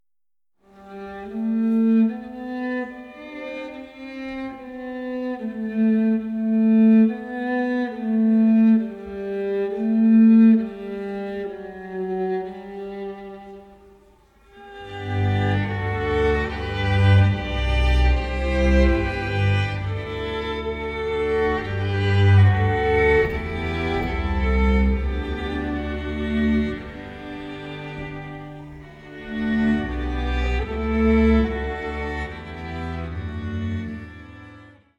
Streichensemble